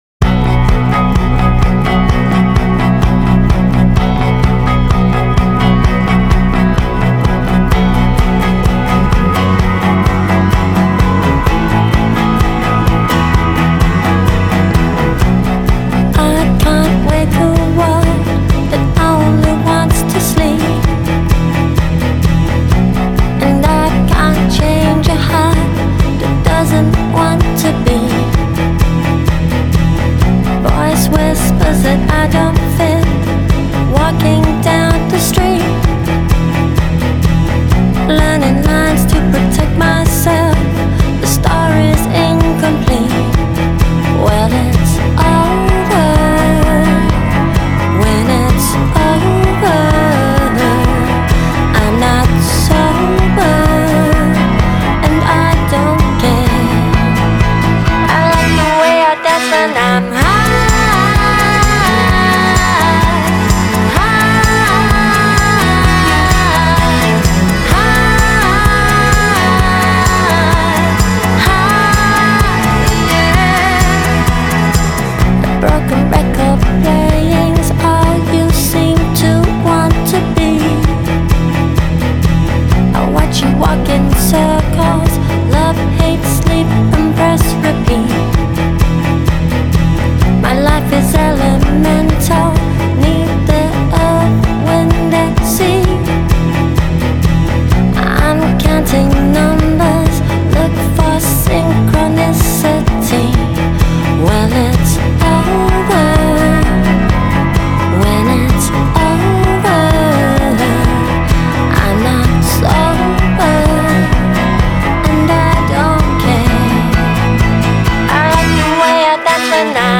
Genre: Electronic, Alternative, Downtempo